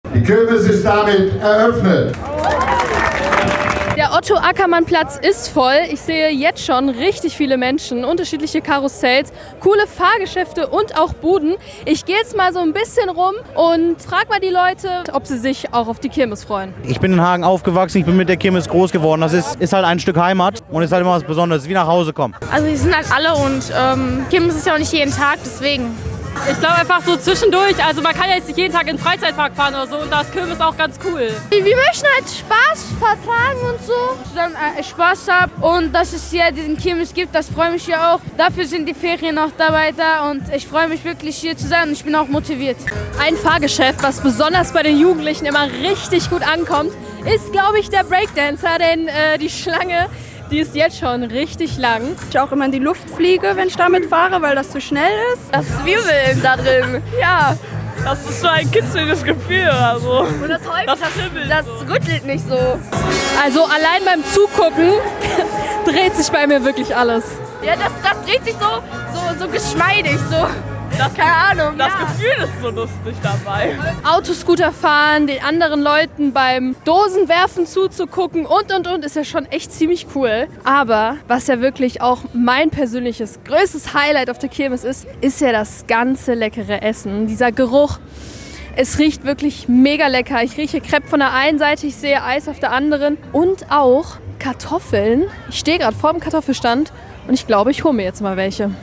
osterkirmes-hagen.mp3